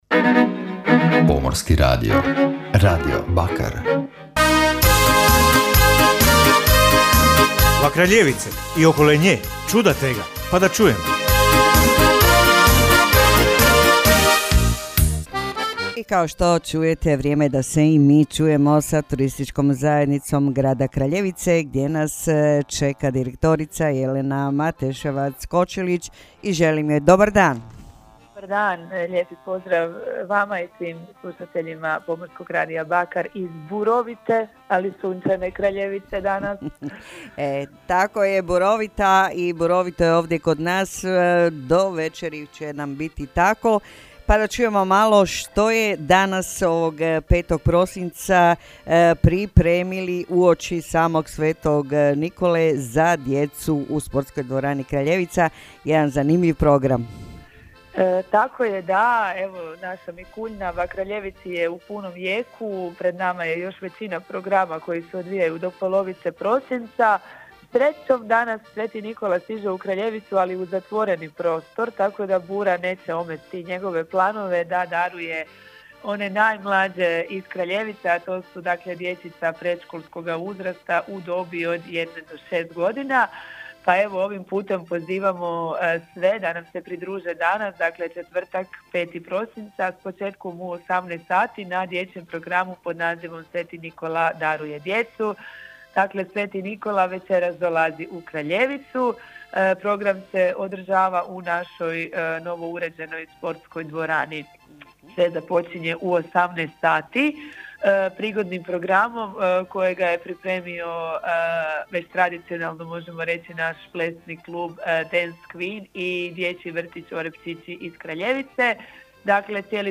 [INTERVJU]